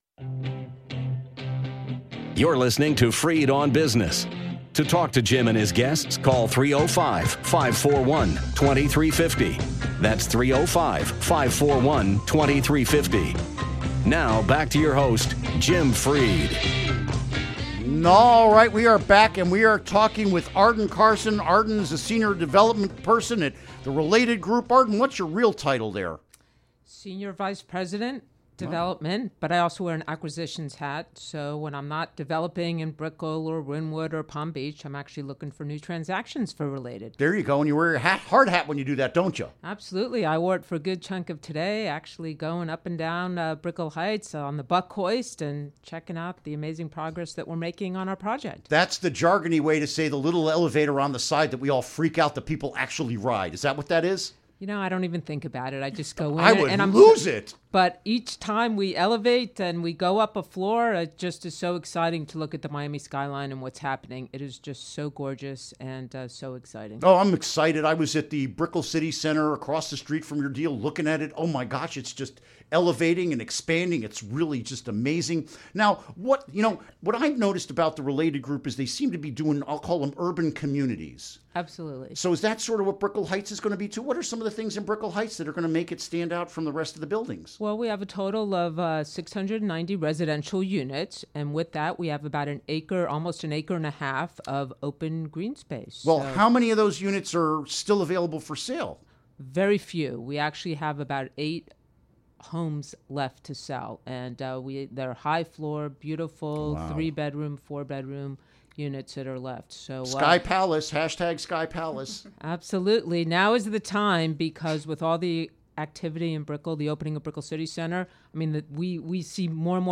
Interview Segment Episode 373: 06-16-16 Download Now!